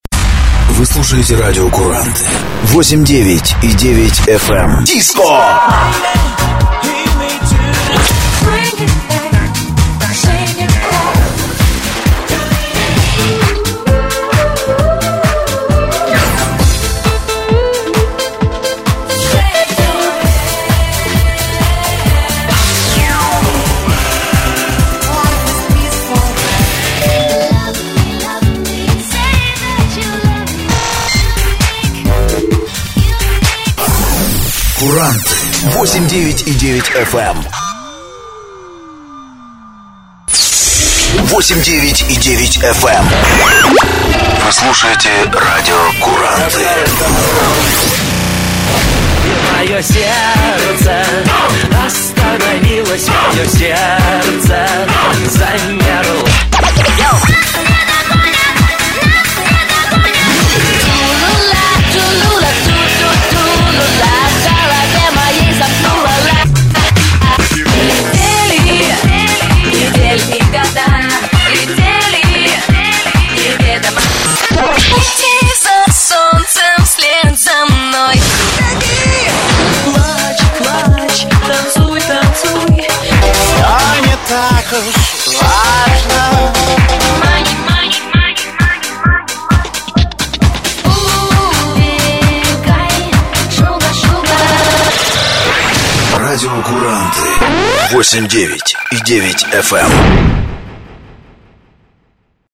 Оцените ёмкость музыкальных фраз и плотность наполнения